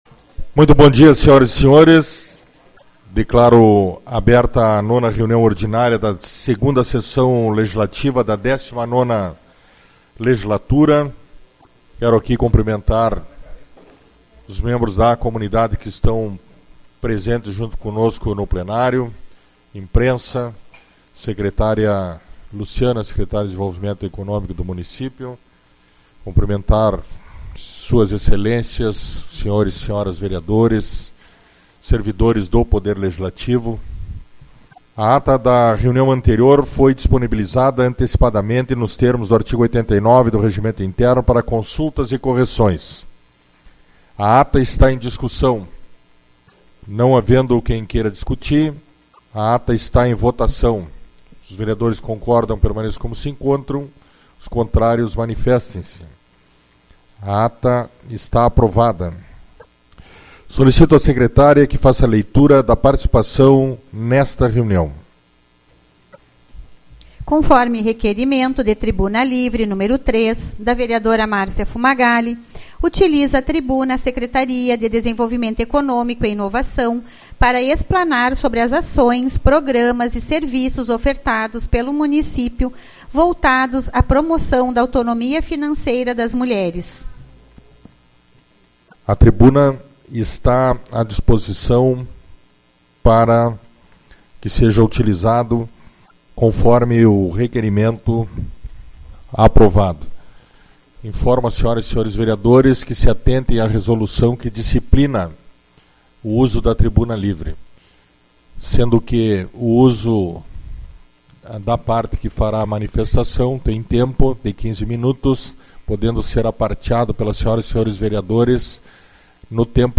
05/03 - Reunião Ordinária